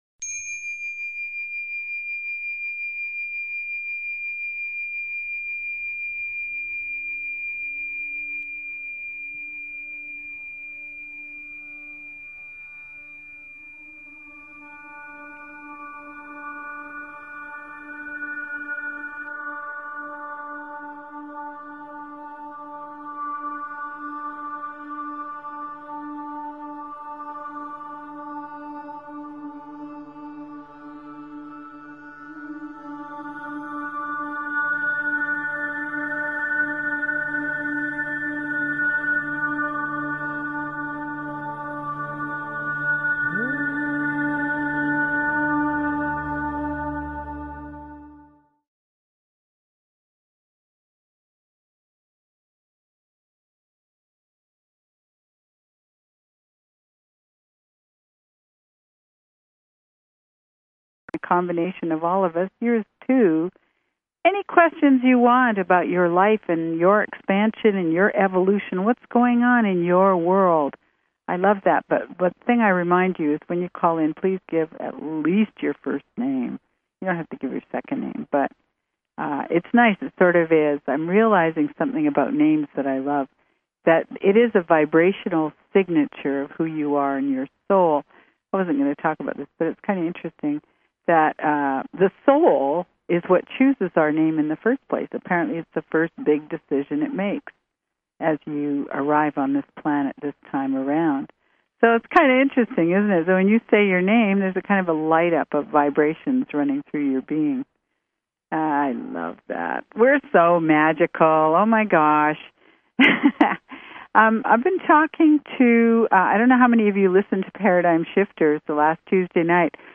Talk Show Episode, Audio Podcast, Radiance_by_Design and Courtesy of BBS Radio on , show guests , about , categorized as
Show Headline Radiance_by_Design Show Sub Headline Courtesy of BBS Radio Radiance by Design - August 30, 2012 Radiance By Design Please consider subscribing to this talk show.